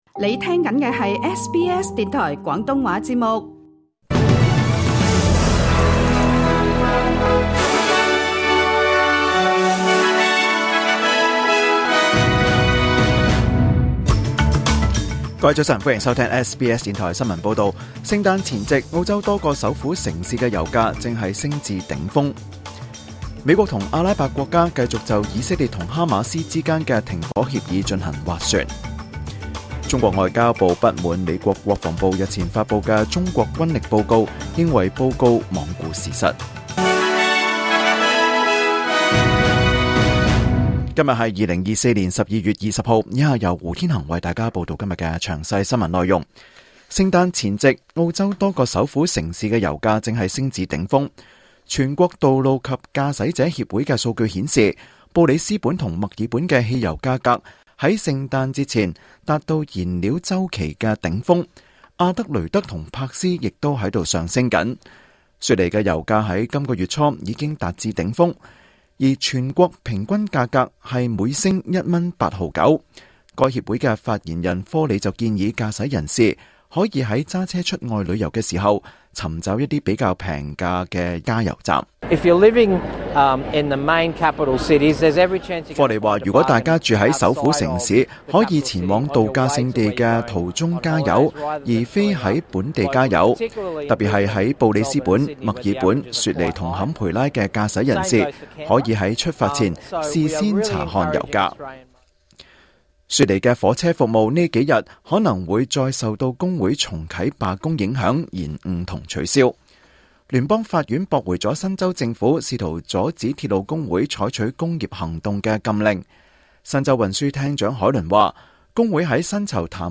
2024年12月20日SBS 廣東話節目詳盡早晨新聞報道。